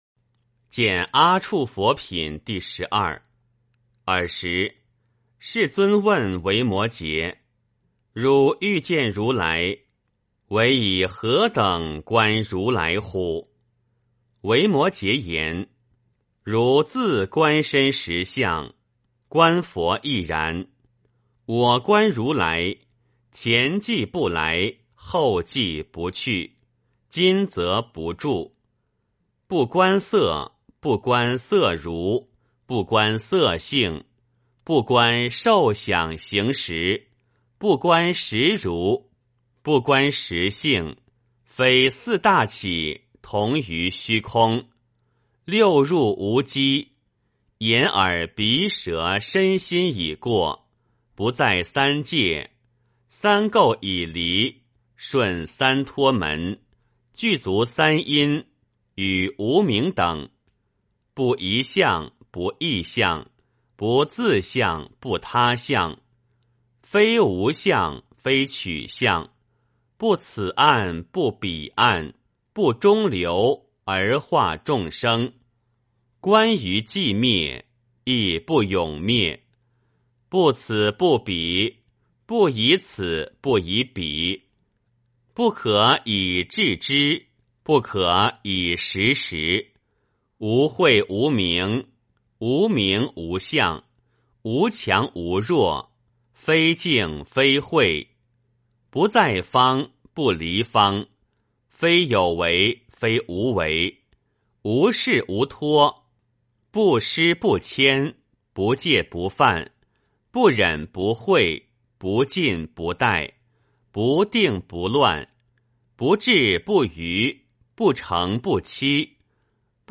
维摩诘经-见阿閦佛品第十二 - 诵经 - 云佛论坛